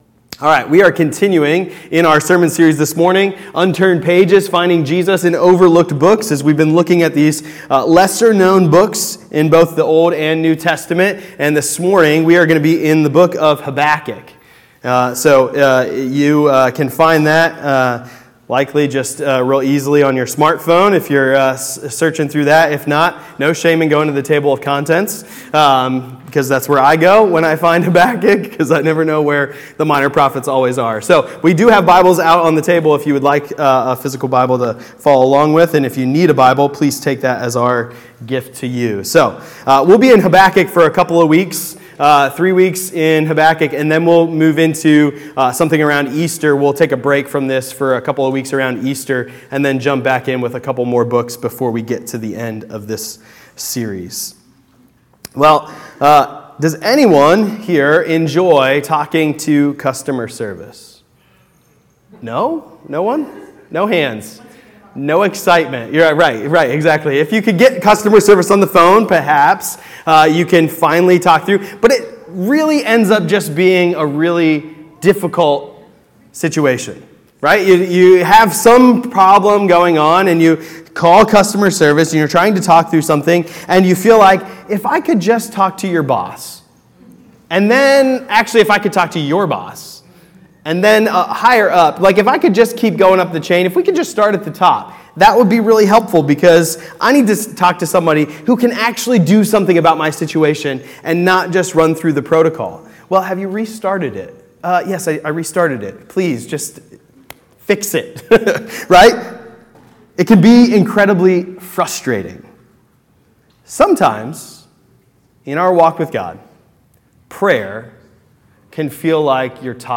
Sermons by City Hope Fellowship